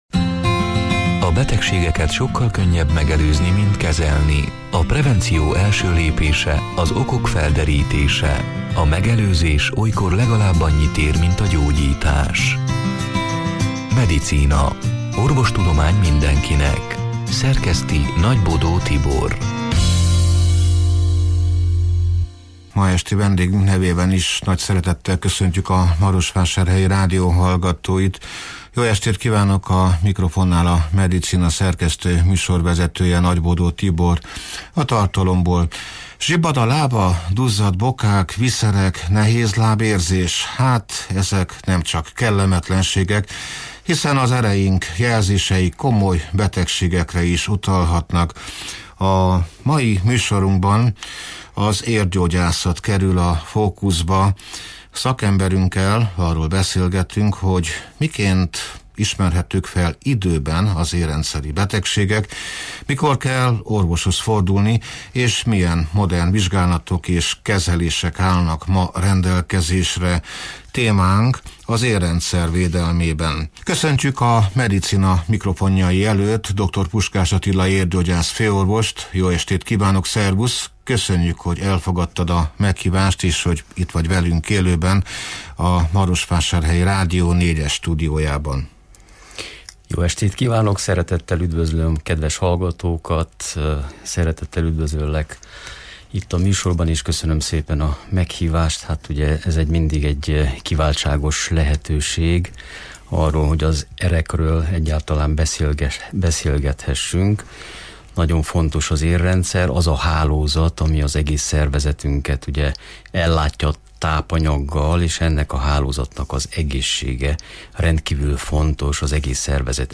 (elhangzott: 2025. június 4-én, szerdán este nyolc órától élőben)